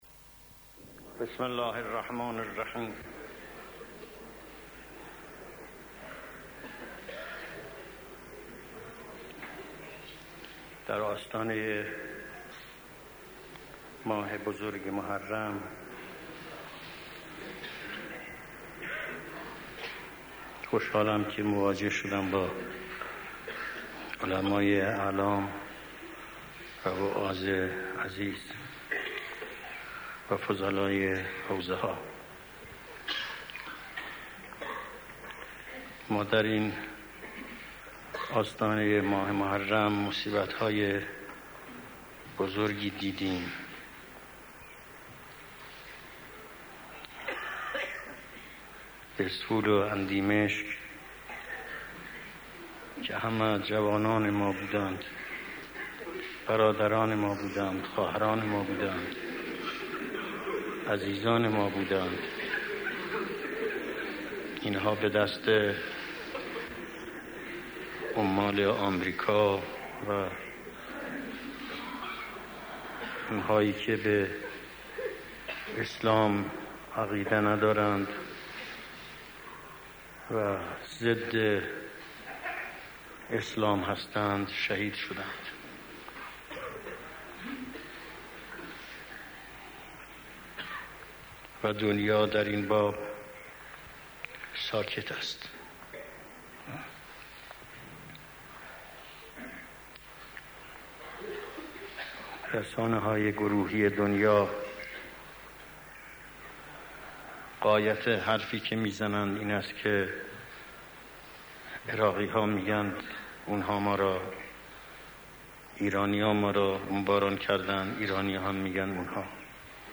حاکمیت اسلام در ایران و وظایف روحانیت حاکمیت اسلام در ایران و وظایف روحانیت ۰:۰۰ ۰:۰۰ دانلود صوت کیفیت بالا عنوان : حاکمیت اسلام در ایران و وظایف روحانیت مکان : تهران، حسینیه جماران تاریخ : ۱۳۶۲-۰۷-۱۳